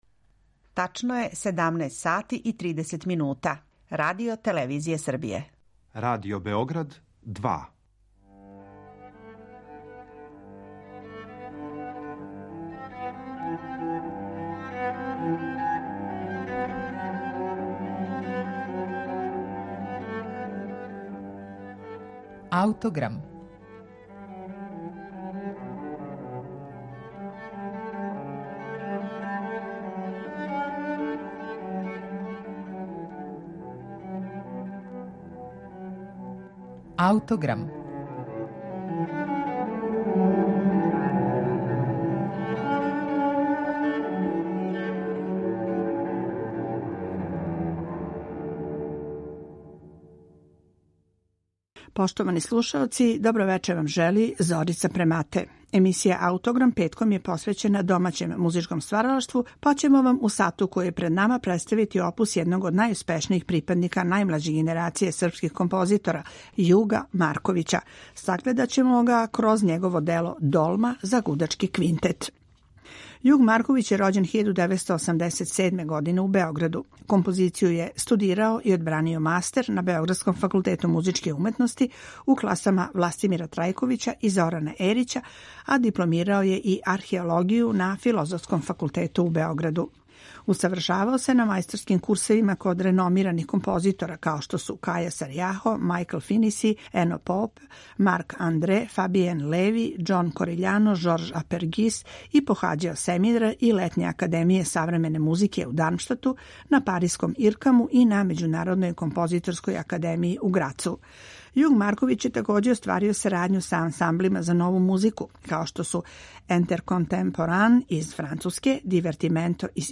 гудачки квинтет
са јавног снимка оствареног приликом премијерног извођења
амерички гудачки квартет